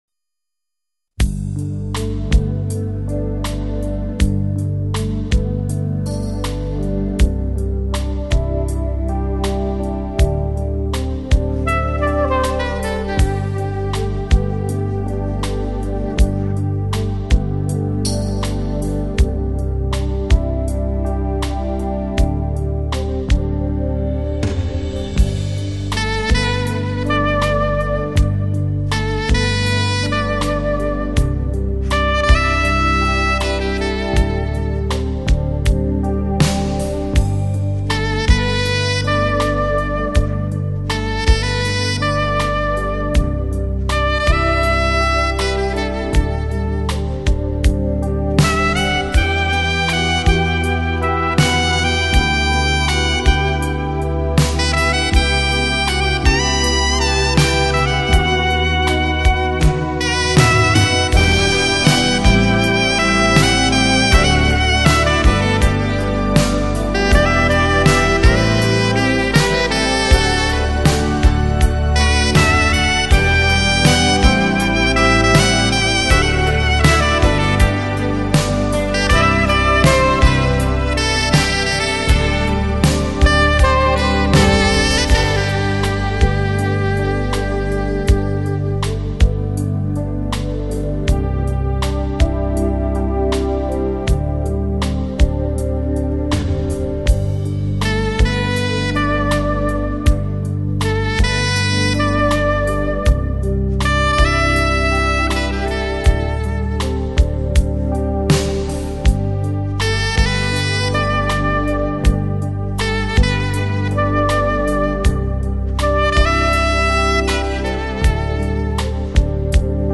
浓烈的情感色彩、令人心旷神怡。